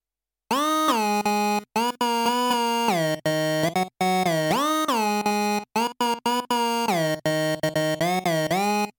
Zwei Pulse-Oscillatoren, einer um zwei Oktaven höher gestimmt. Bei dem tiefen Osc hab ich den Pulsweitenverhältnisoffset auf 50% gestellt, beim höheren auf 0%. Dann noch Portamentofunktion rein und fertig. Bei mir klingst noch etwas mittig, im Video hat der Sound noch einen Badewannen-EQ bekommen, also Bass rein, Mitten raus, Höhen rein.